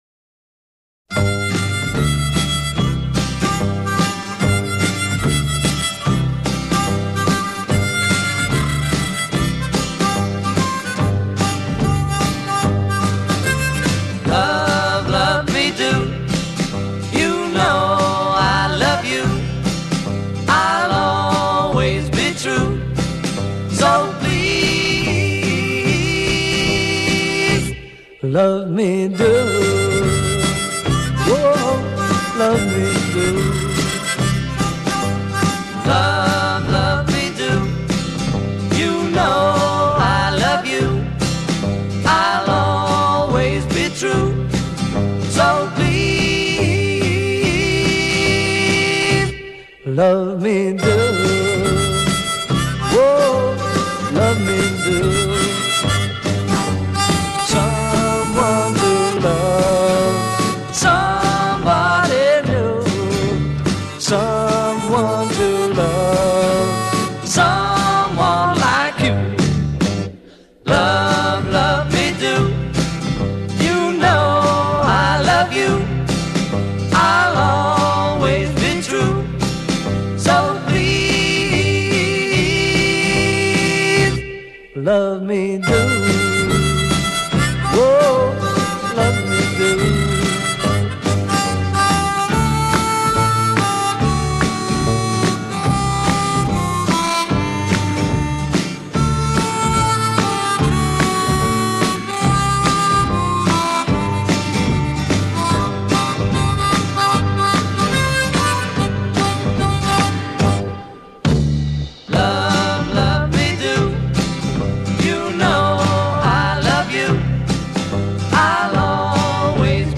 A' Intro 0:00 8 harmonica theme
A verse 0:14 9+4 vocal duet a
B chorus 0:57 8 vocal solo to duet at the end of each line b
B chorus 1:32 8 Harmonica solo as above; add hand claps.
A" coda 2:06 8+ repeat hook and fade a